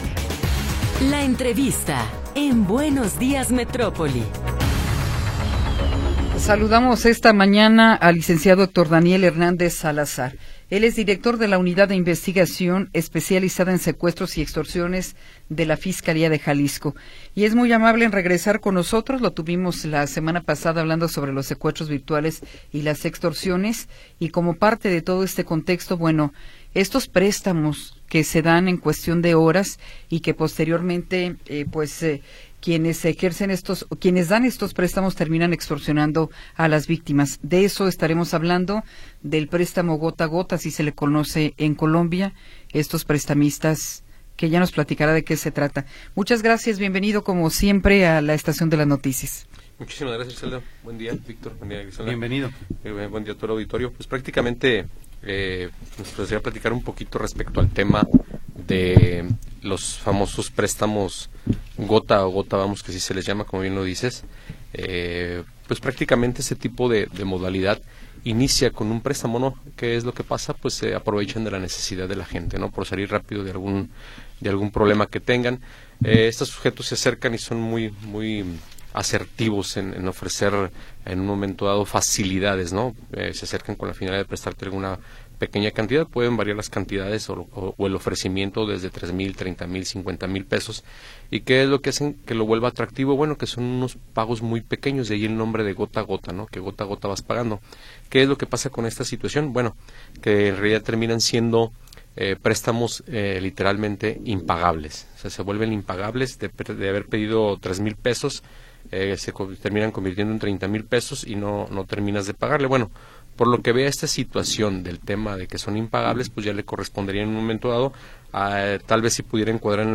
Entrevista con Héctor Daniel Hernández Salazar